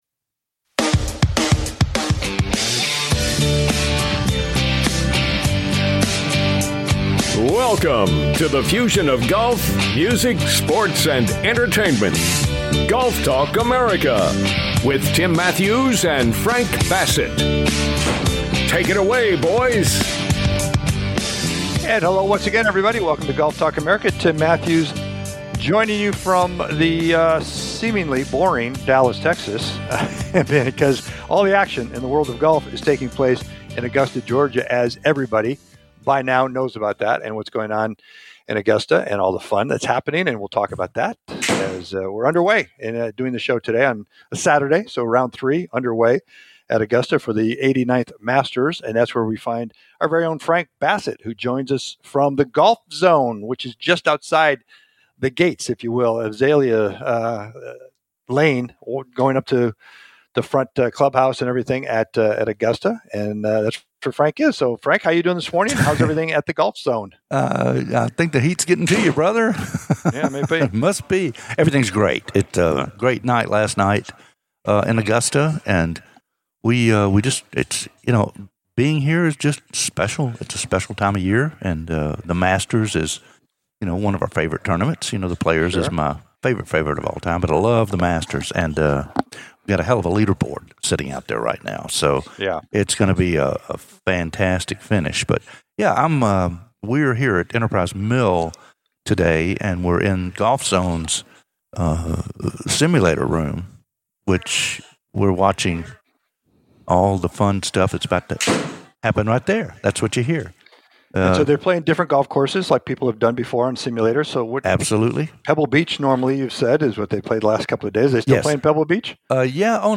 "LIVE" FROM THE MASTERS. IT'S MOVING DAY
It's moving day in Augusta...